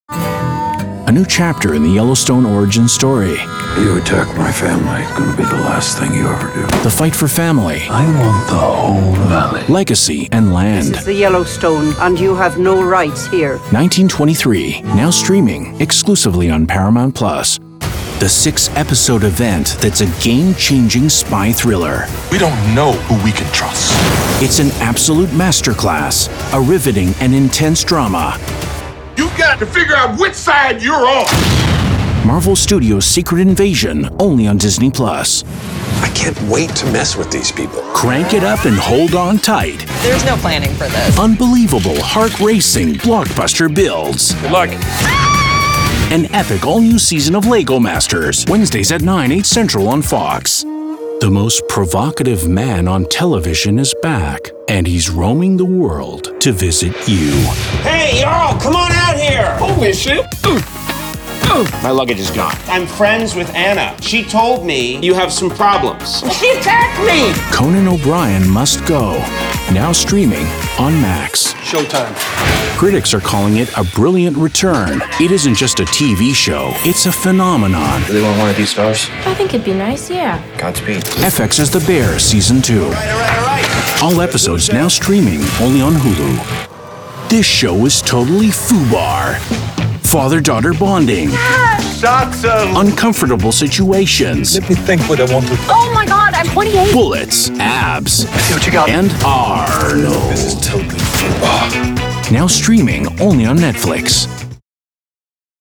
Promo Demo
Middle Aged
My broadcast quality studio includes an acoustically treated iso-booth and industry standard equipment including:
-Sennheiser MKH-416 mic